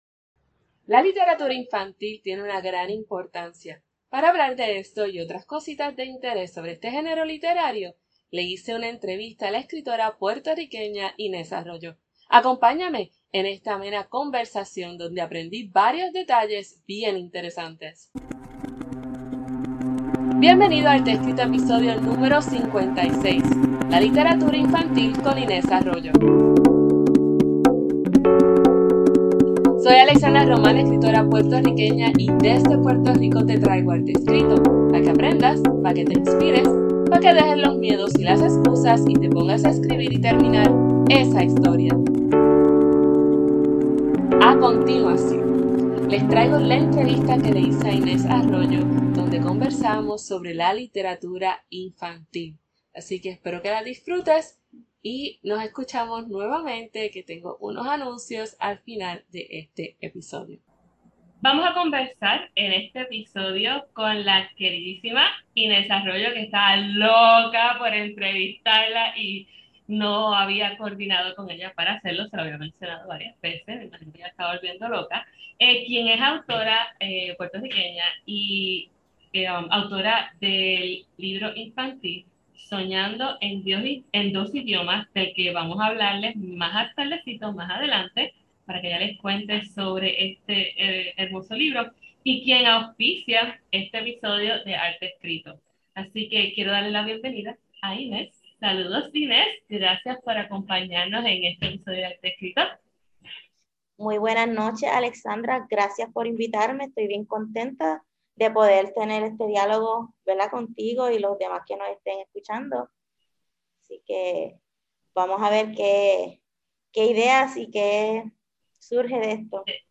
Acompáñame en esta amena conversación donde aprendí varios detalles bien interesantes.